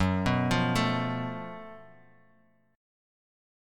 F#mM7bb5 chord